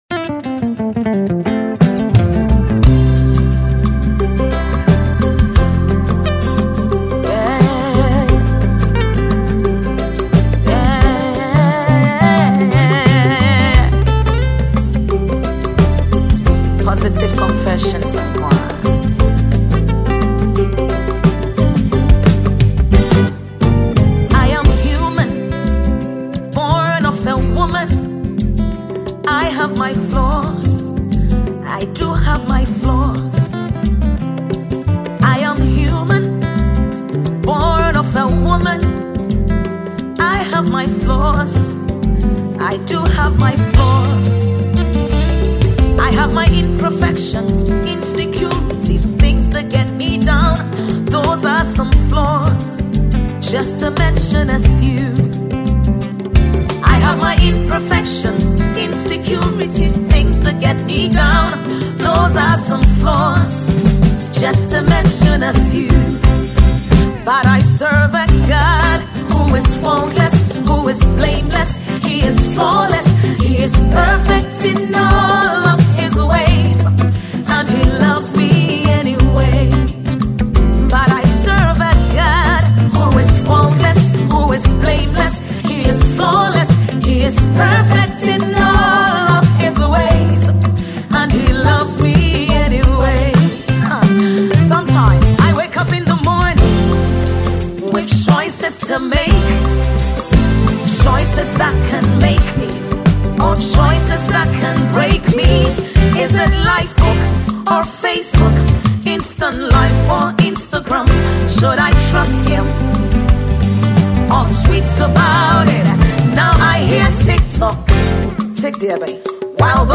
a Ghanaian female gospel songstress